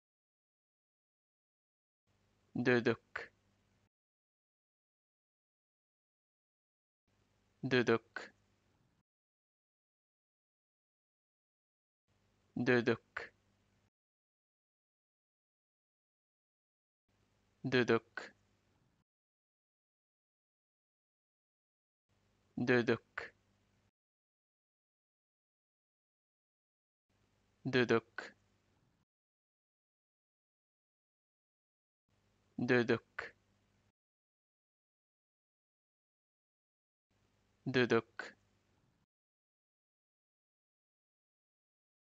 Audio file of the word "Cifteli"